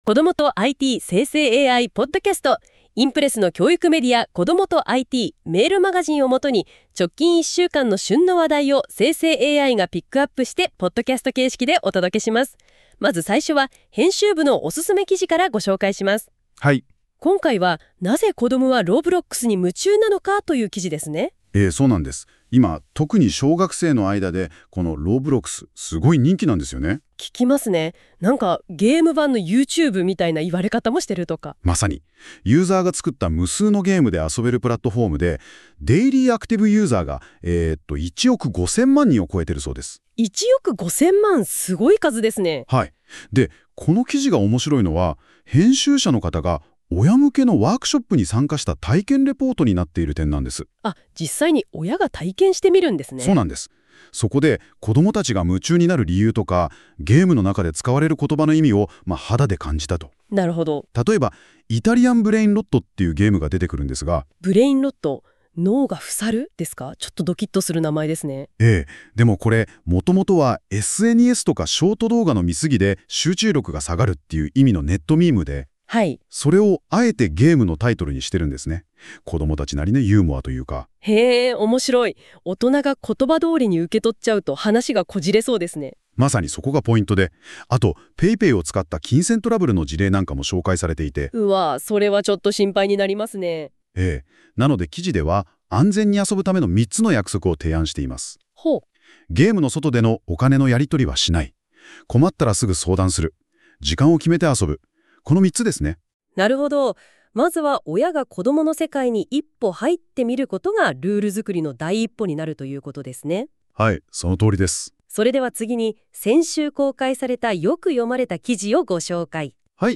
※生成AIによる読み上げは、不自然なイントネーションや読みの誤りが発生します。 ※この音声は生成AIによって記事内容をもとに作成されています。